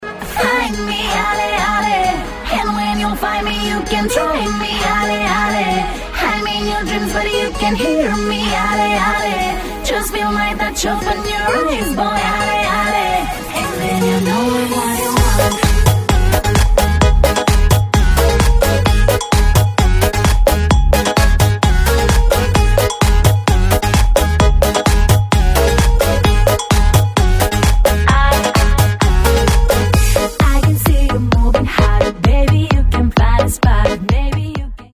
Romaneasca